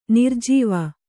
♪ nirjīva